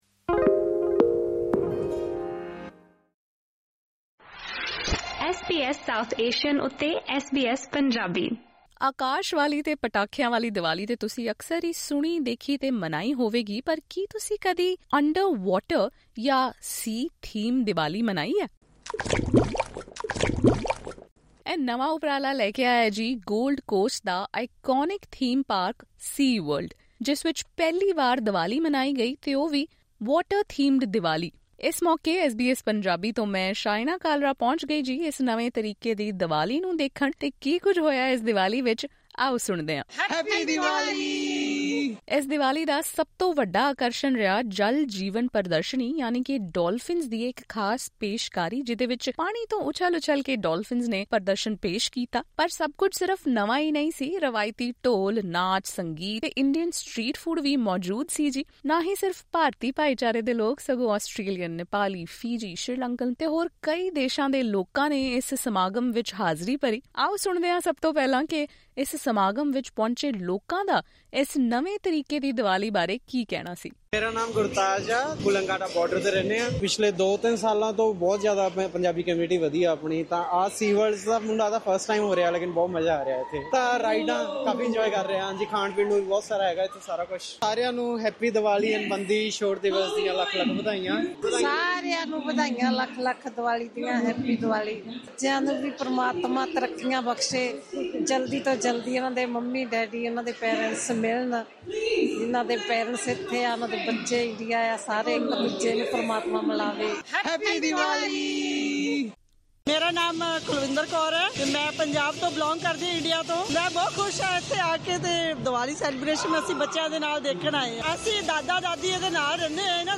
Listen to this sea-themed Diwali, through this podcast....
ਸਮੁੰਦਰੀ ਥੀਮ ਵਾਲੀ ਦੀਵਾਲੀ ਦੀ ਝਲਕੀਆਂ